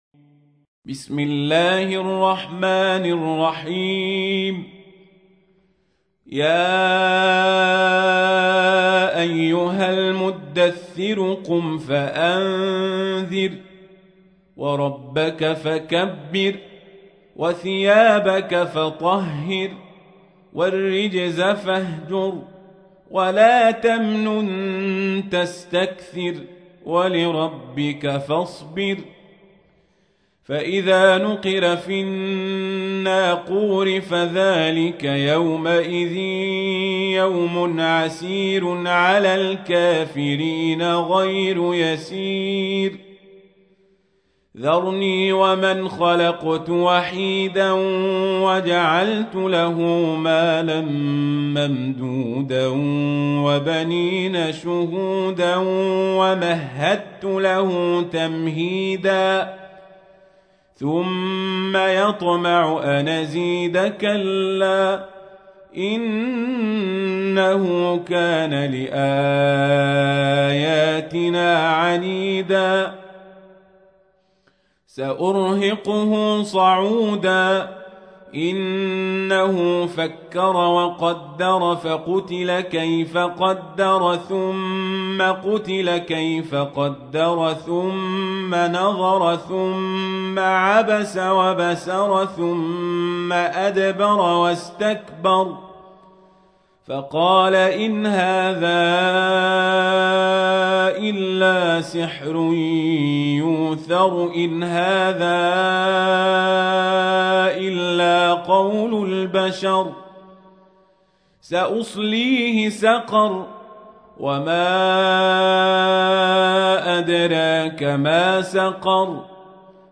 تحميل : 74. سورة المدثر / القارئ القزابري / القرآن الكريم / موقع يا حسين